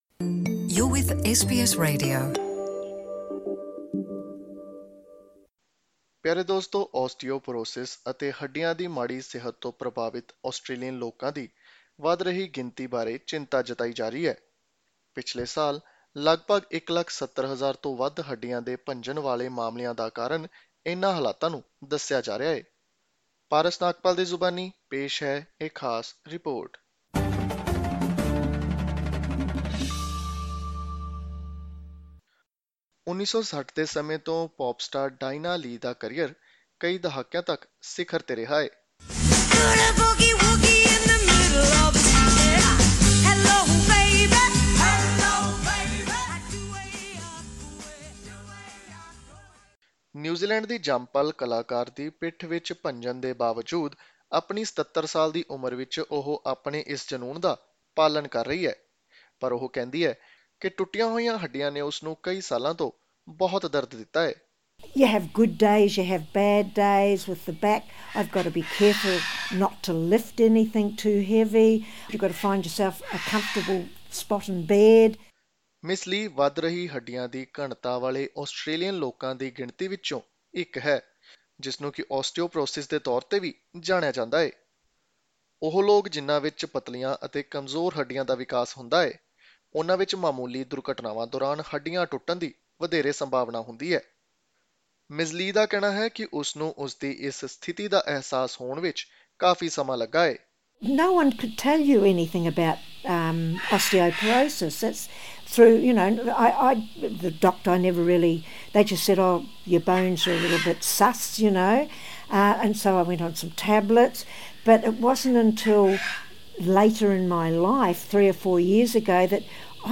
To hear the full report click on the audio link above.